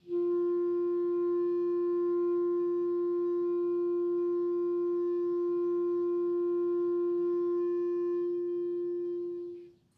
Clarinet
DCClar_susLong_F3_v1_rr1_sum.wav